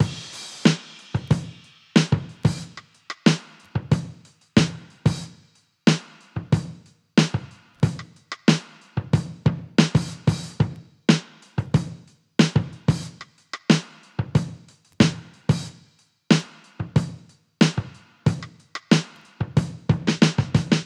• 92 Bpm Drum Loop Sample E Key.wav
Free breakbeat sample - kick tuned to the E note.
92-bpm-drum-loop-sample-e-key-PHb.wav